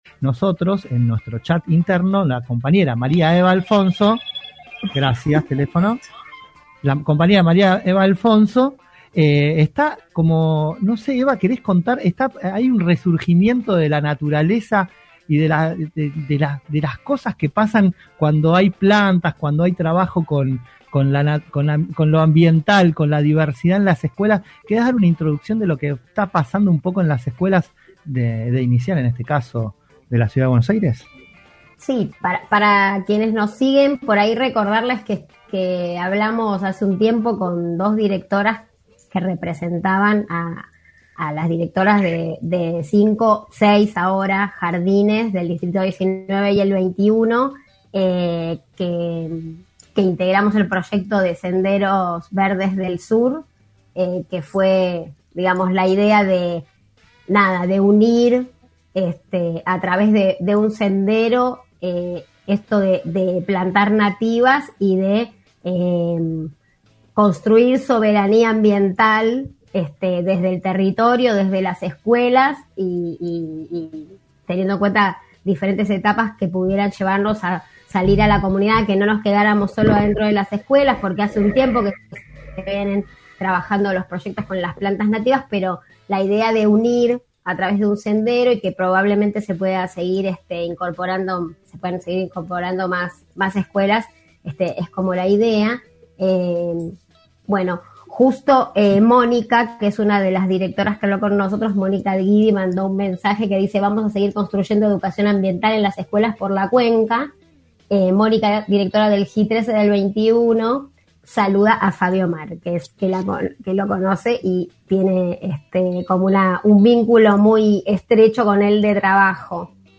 Doska Radio / Entrevista